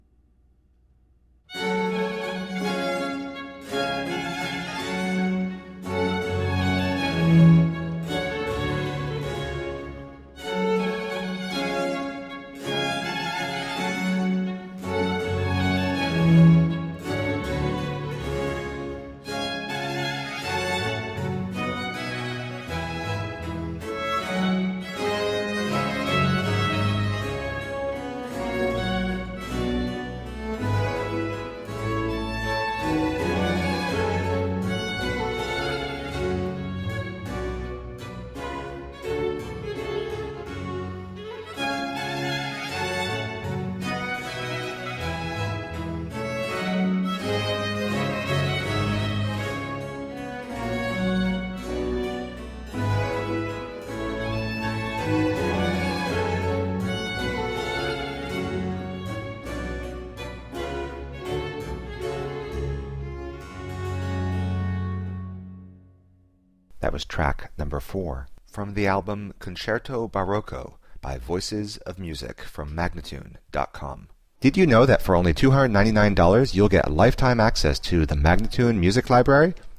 Baroque Concertos